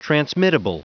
Prononciation du mot transmittable en anglais (fichier audio)
Prononciation du mot : transmittable